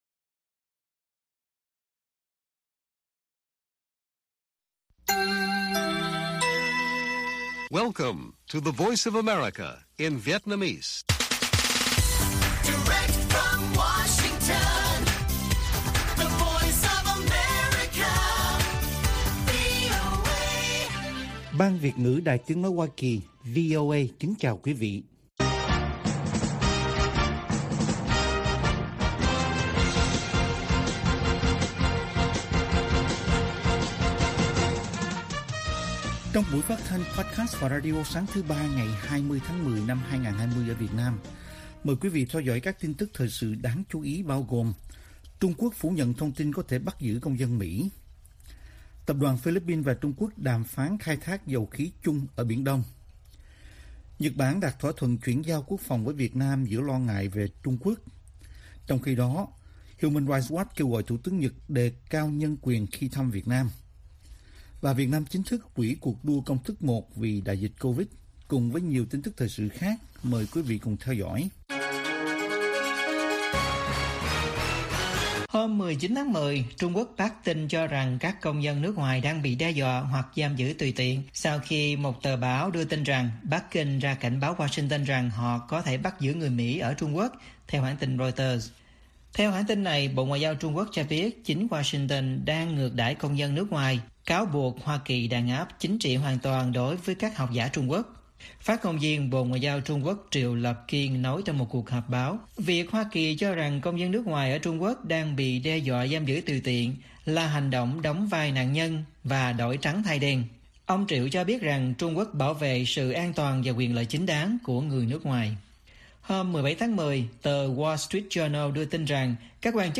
Bản tin VOA ngày 20/10/2020